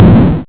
cut_tree.wav